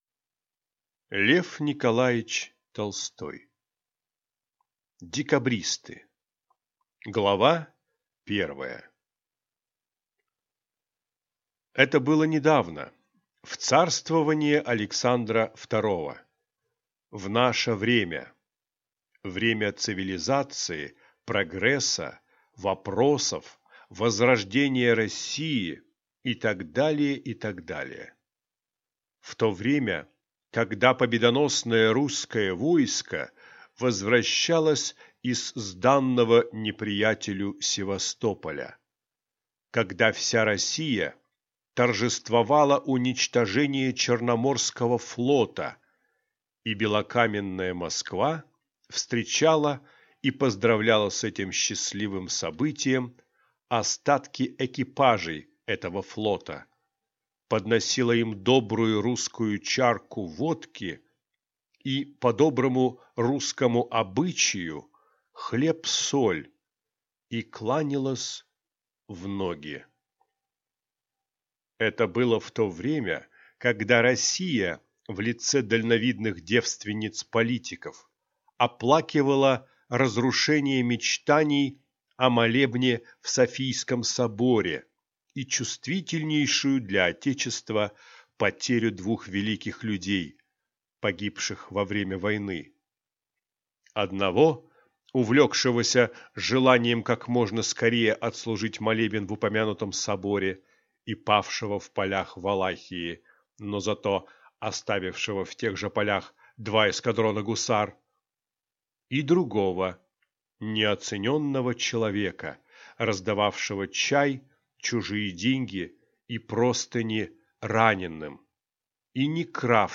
Аудиокнига Декабристы | Библиотека аудиокниг